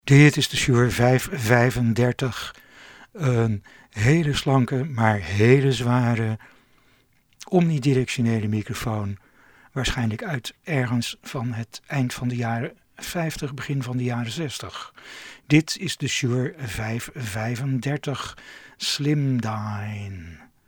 Shure realiseerde zich de behoefte aan zo'n handheld-microfoon en presenteerde in 1955 de 'Slendyne' 530 (Slender Dynamic = Slanke Dynamische) omni directionele microfoon, voor PA en broadcast, voor $ 110.
Shure 535 sound NL.mp3